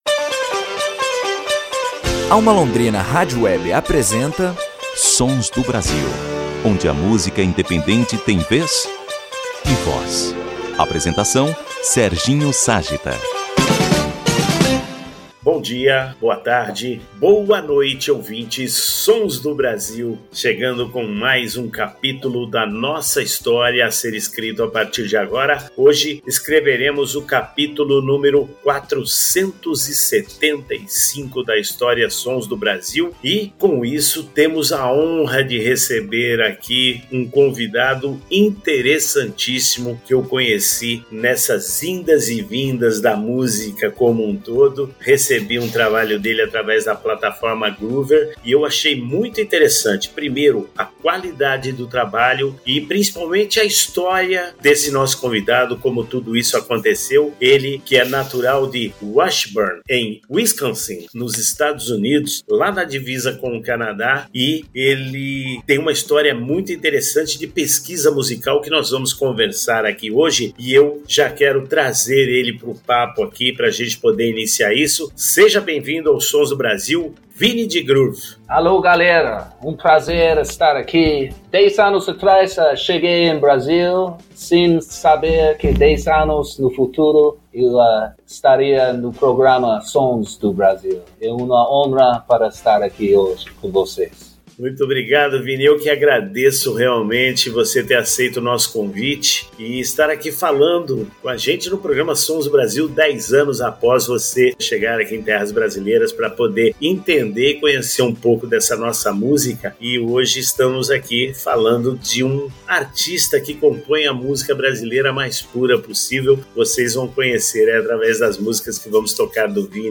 entrevista principal